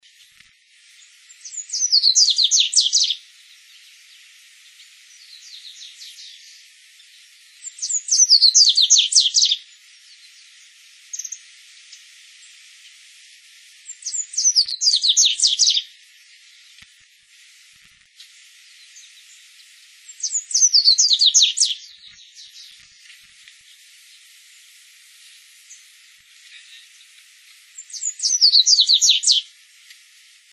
Double-collared Seedeater (Sporophila caerulescens)
Sex: Male
Life Stage: Adult
Location or protected area: Reserva Ecológica Costanera Sur (RECS)
Condition: Wild
Certainty: Photographed, Recorded vocal